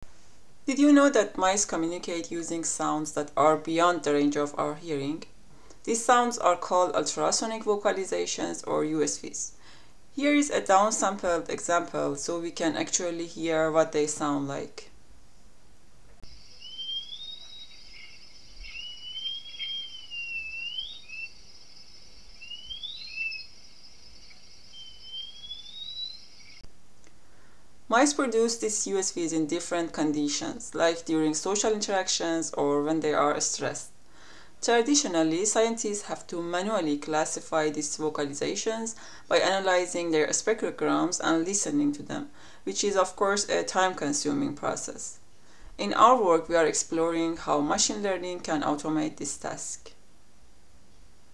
An audiocarnet made for the GDR IASIS research day on audio synthesis, held at Ircam on November 7th, 2024.